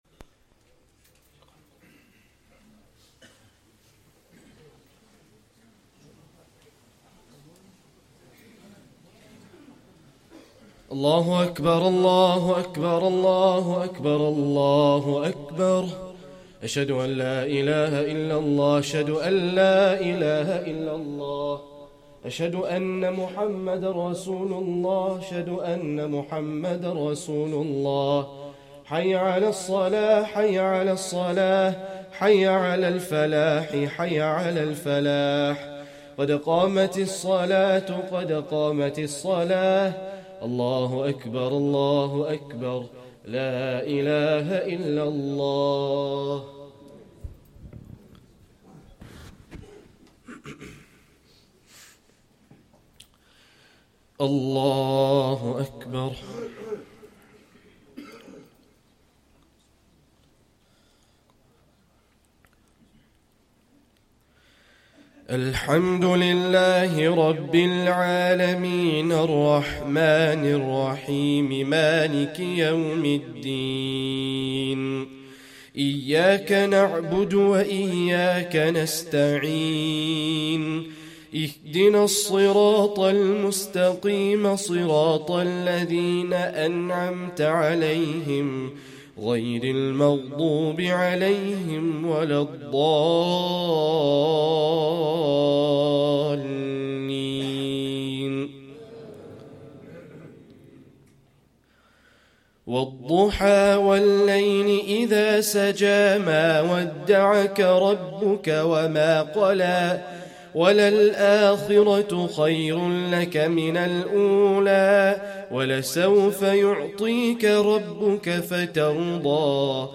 Esha + 1st Taraweeh Prayer - 2nd Ramadan 2024